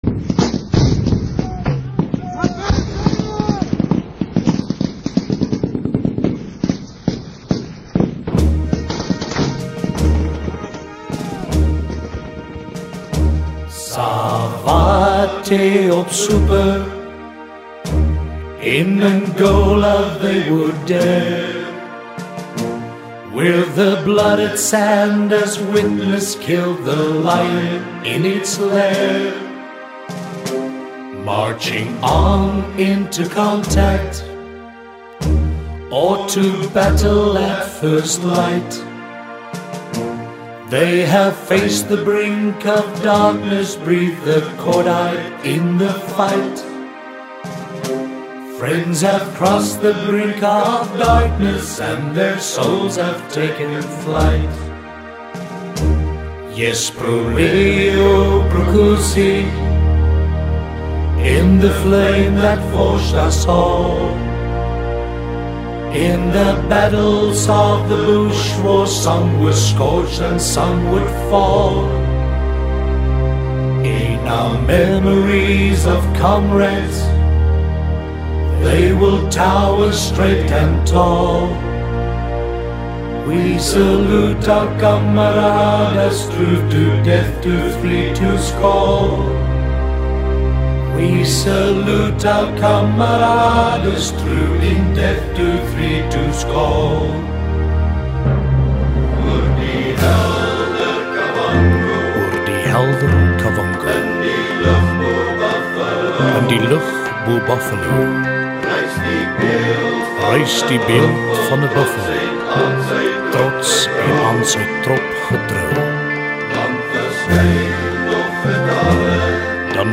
haunting song
had been adopted as the unit’s personal funeral dirge.